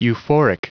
Prononciation du mot euphoric en anglais (fichier audio)
euphoric.wav